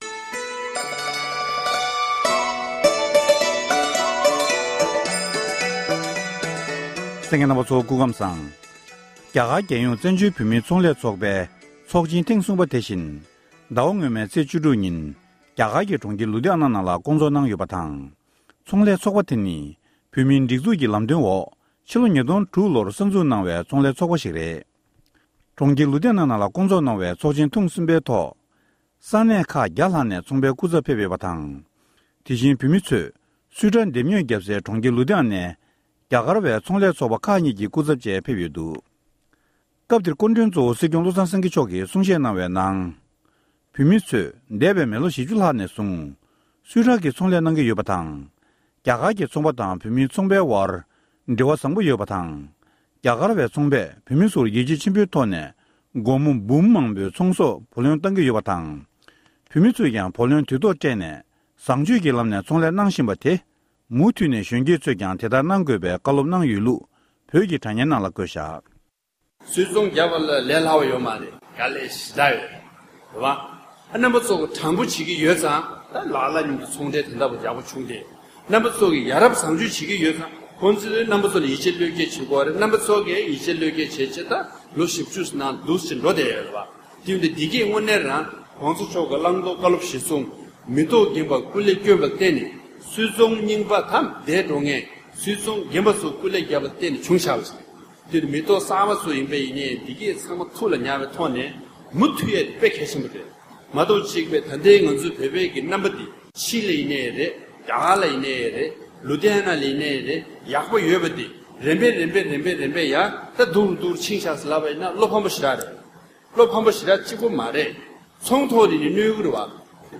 སྲིད་སྐྱོང་མཆོག་ནས་བོད་མིའི་ཚོང་ལས་ཚོགས་པའི་ཚོགས་ཆེན་ཐེངས་གསུམ་པའི་ཐོག་བཀའ་སློབ་གནང་བ།